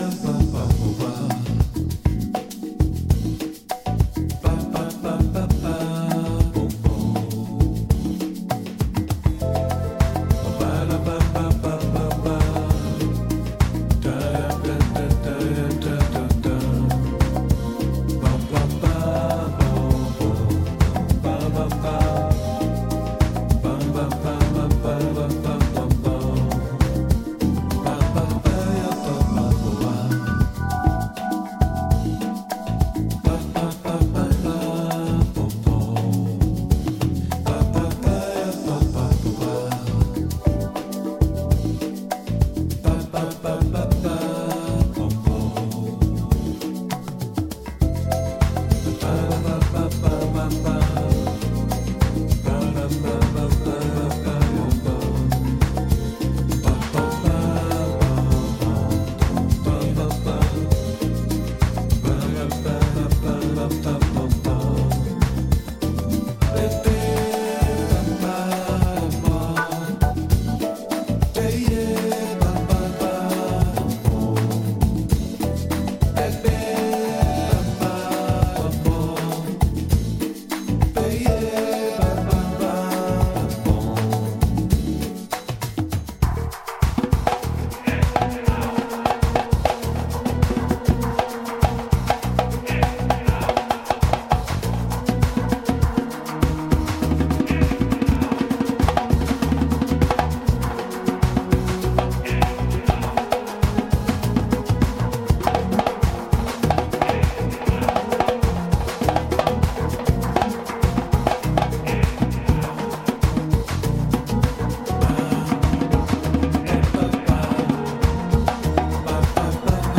soft and euphoric relaxed mood
bossa nova